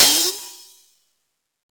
• Open High-Hat Sound G Key 05.wav
Royality free open high-hat sample tuned to the G note. Loudest frequency: 6248Hz
open-high-hat-sound-g-key-05-hrs.wav